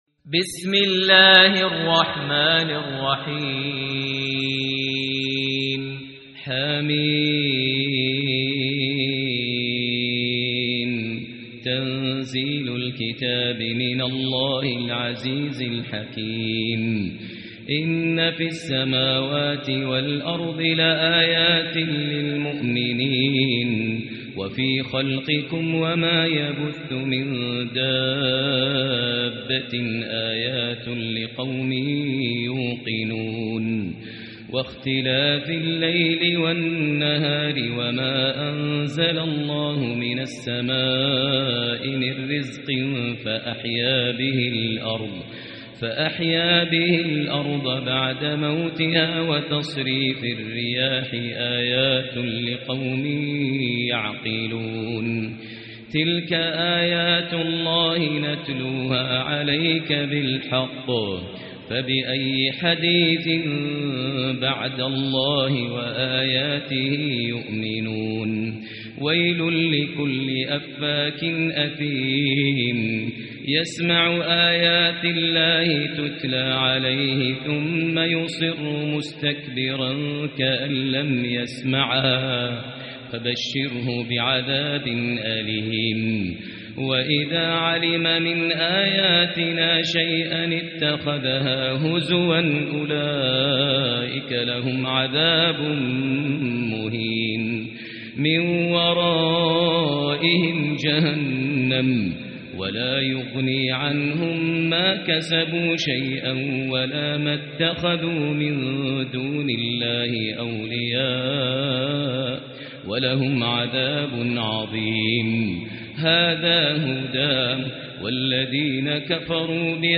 سورة الجاثية | مصحف الحرم المكي ١٤٤٤ > مصحف تراويح الحرم المكي عام ١٤٤٤ > المصحف - تلاوات الحرمين